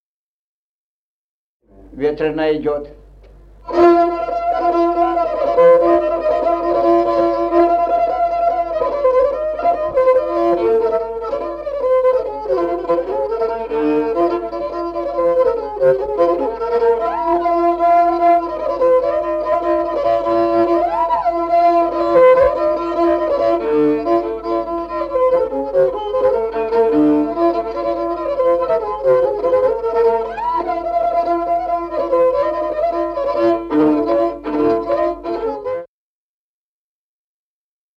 Музыкальный фольклор села Мишковка «Ветреная», припевки, репертуар скрипача.